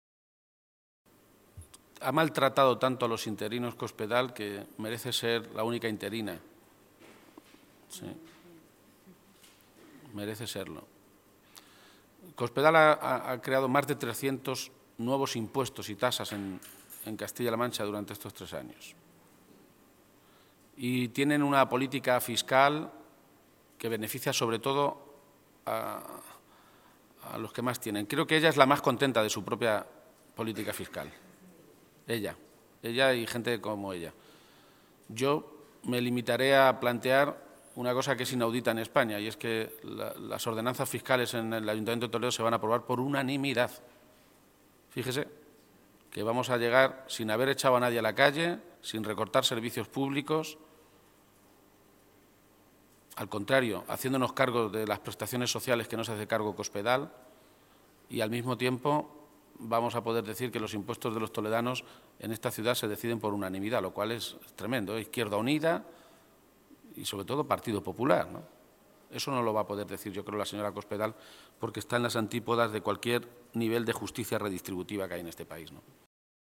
García-Page se pronunciaba de esta manera esta mañana, en Toledo, a preguntas de los medios de comunicación en una comparecencia que se producía pocas horas antes de que el Pleno de la Asamblea autonómica, esta tarde, a partir de las cuatro, debata y vote esa iniciativa socialista.
Cortes de audio de la rueda de prensa